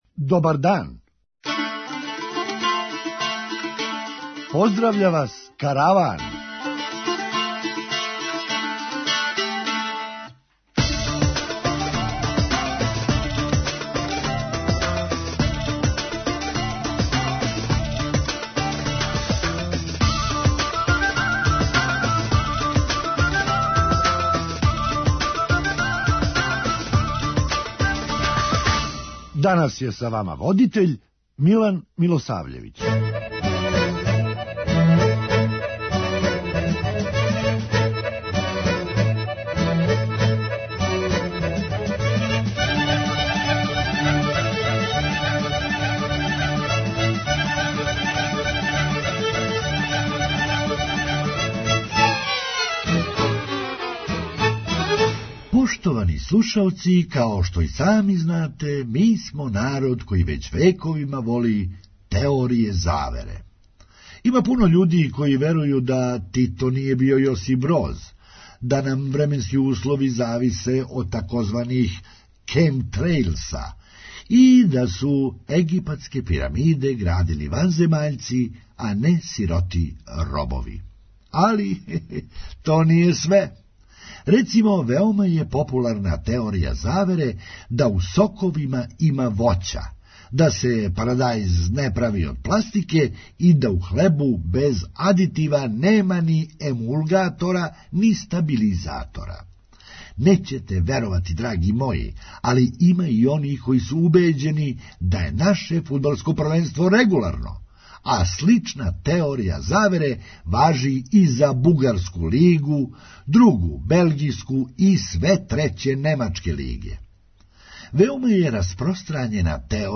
Хумористичка емисија
Наш човек ни рођеног кума не би носио по улици а камоли свастику. преузми : 8.95 MB Караван Autor: Забавна редакција Радио Бeограда 1 Караван се креће ка својој дестинацији већ више од 50 година, увек добро натоварен актуелним хумором и изворним народним песмама.